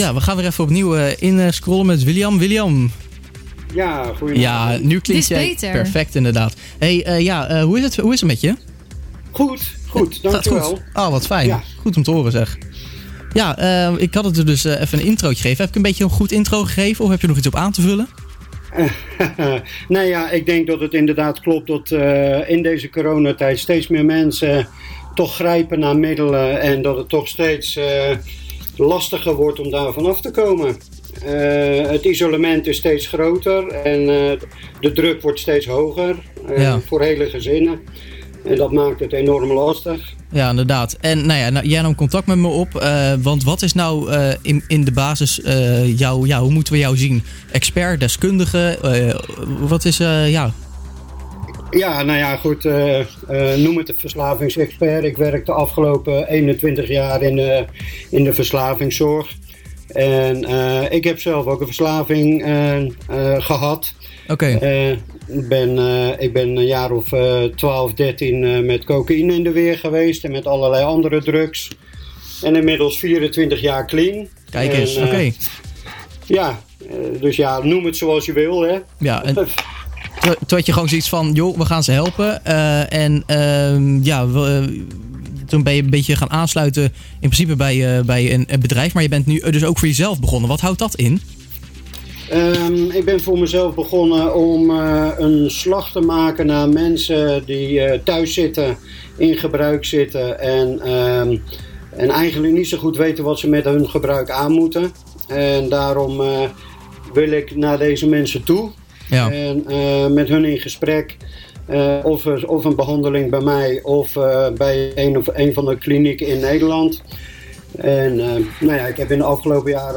Interview bij radio programma " Goed Weekend "
Deze week een gesprek met mij over :